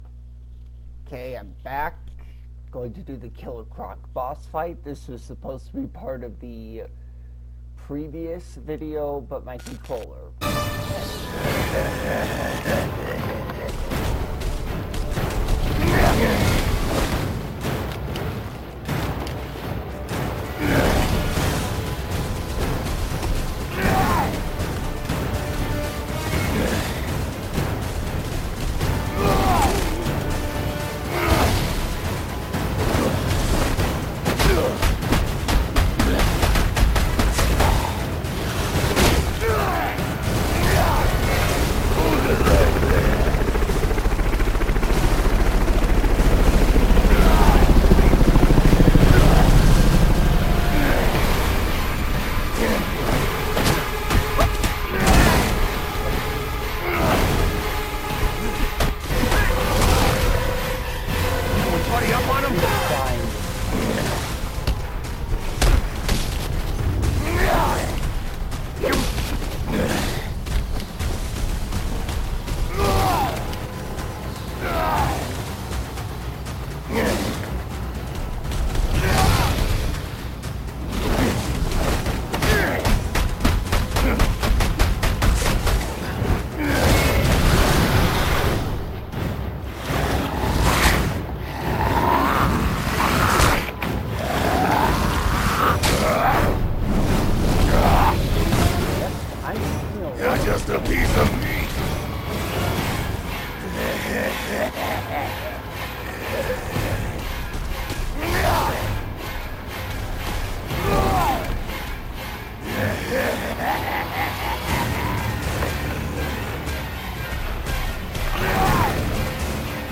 I play Batman Arkham Origins with commentary